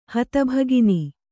♪ hata bhāgini